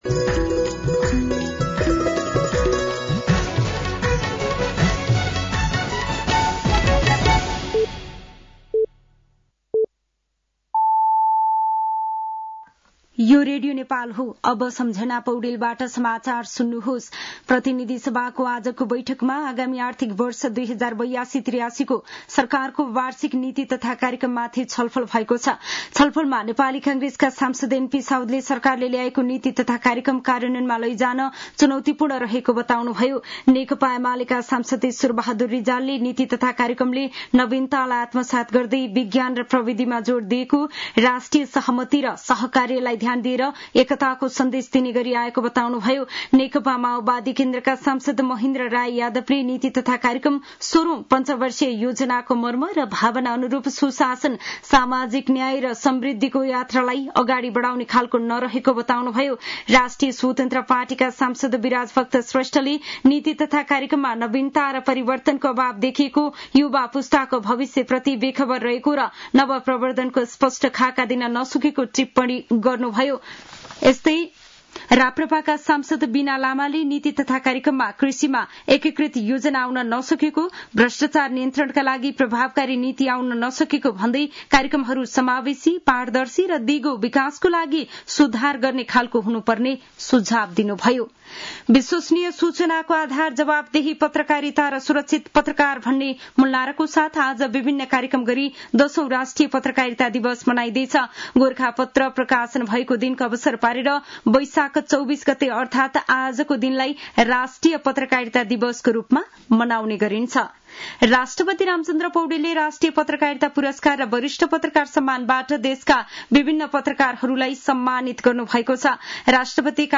साँझ ५ बजेको नेपाली समाचार : २४ वैशाख , २०८२